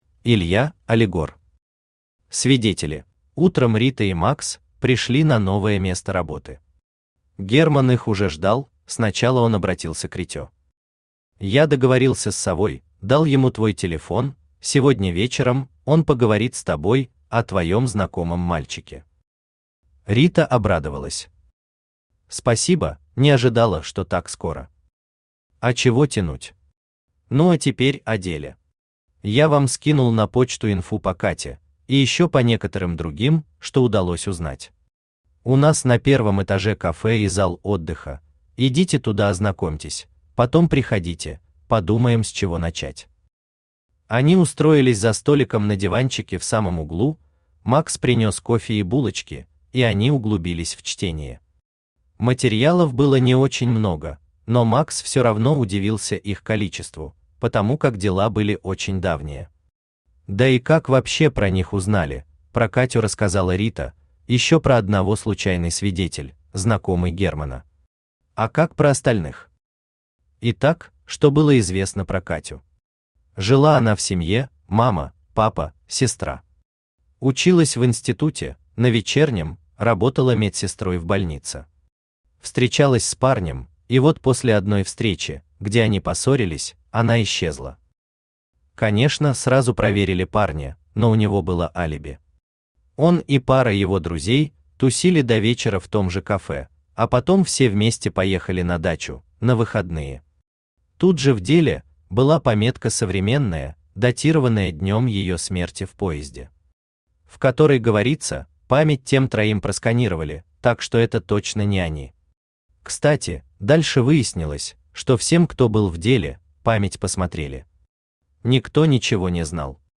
Aудиокнига Свидетели Автор Илья Алигор Читает аудиокнигу Авточтец ЛитРес.